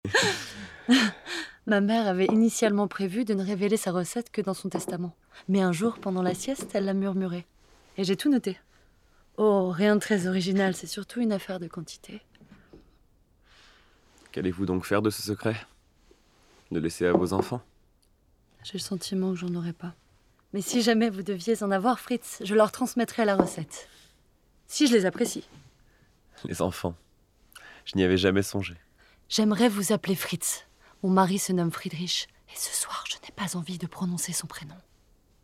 IMDA - Doublage - Beloved sisters